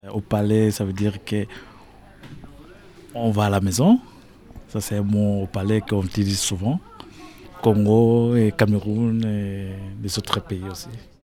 Au palais play all stop uitspraak Au palais.